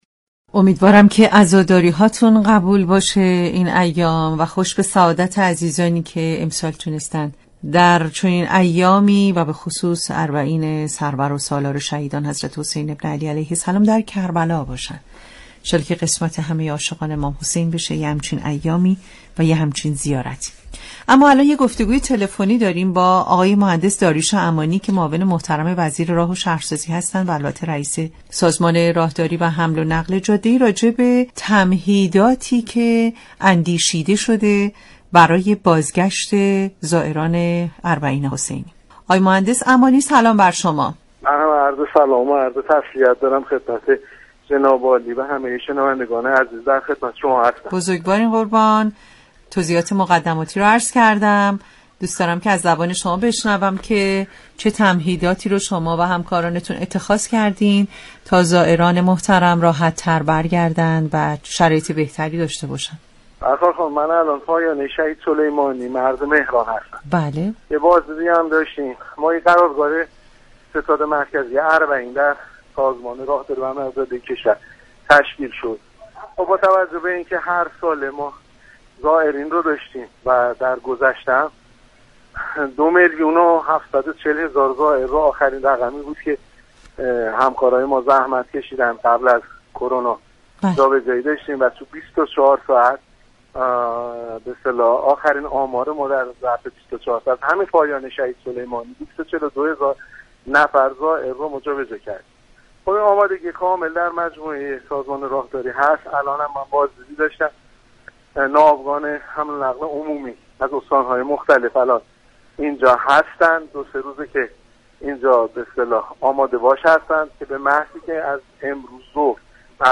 رئیس سازمان راهداری و حمل و نقل جاده ای در گفتگو با رادیو پیام